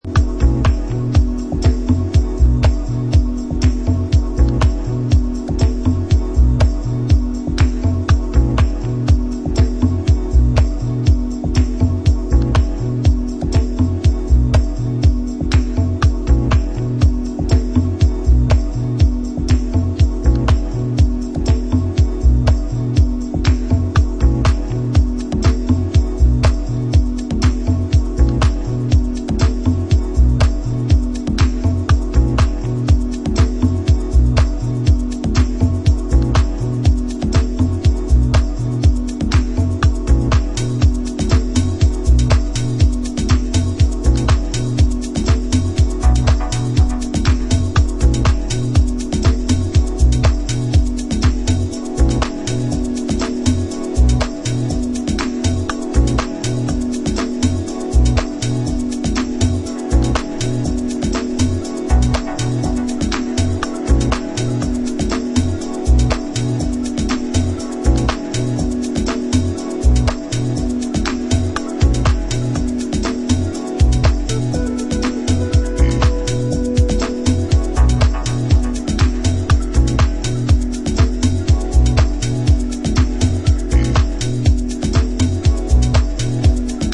nice smooth deep house tracks